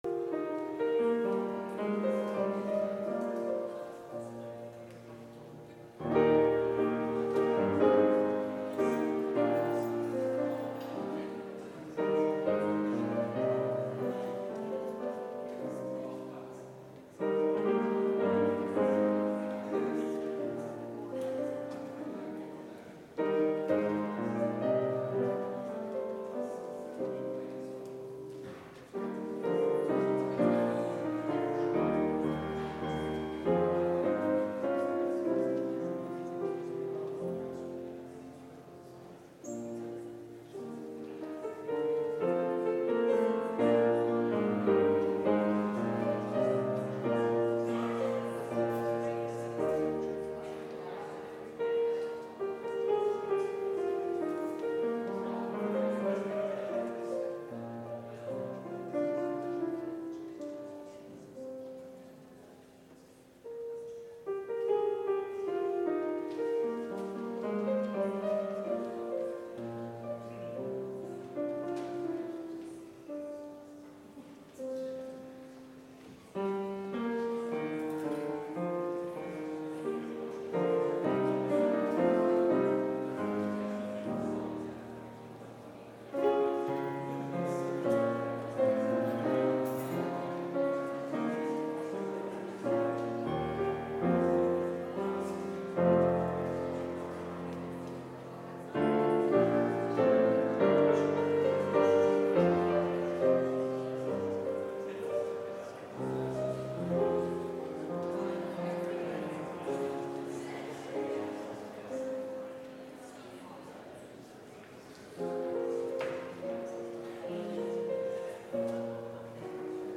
Complete service audio for Chapel - Thursday, April 11, 2024